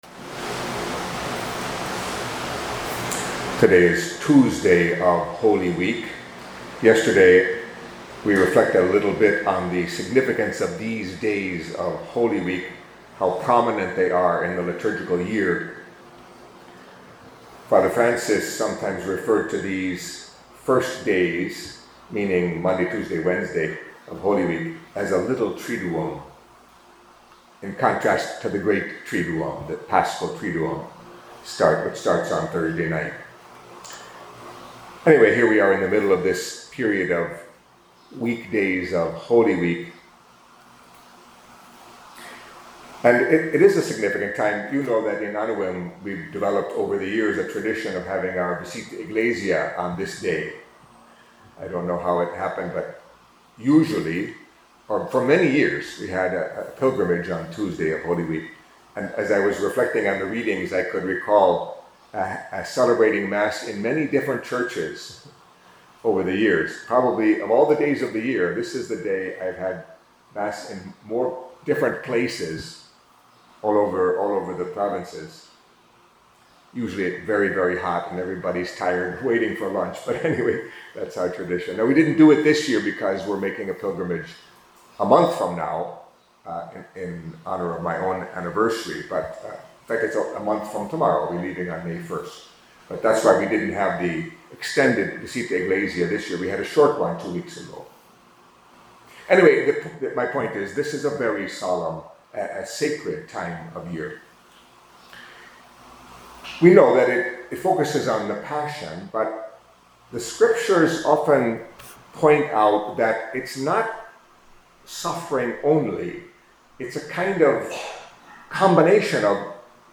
Catholic Mass homily for Tuesday of the Holy Week